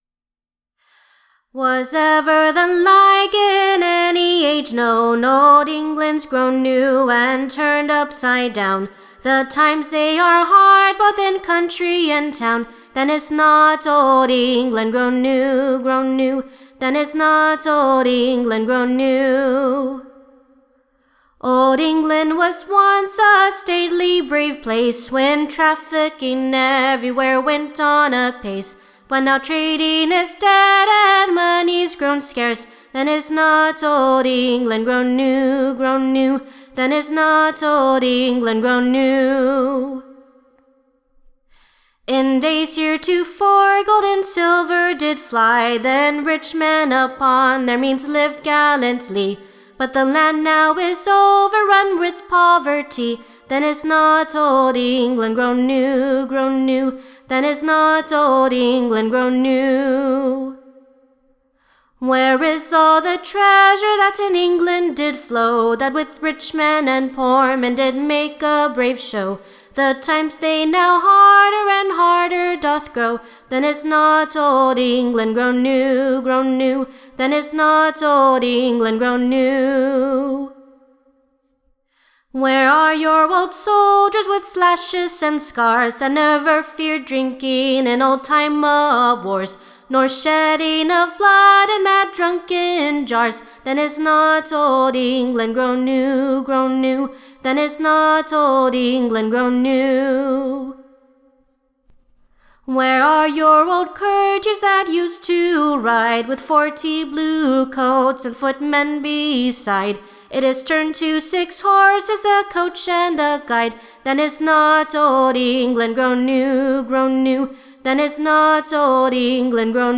Recording Information Ballad Title A Description of Old England, Or, / A true Declaration of the times.
Standard Tune Title Greensleeves Media Listen 00 : 00 | 16 : 28 Download c1.1244.mp3 (Right click, Save As)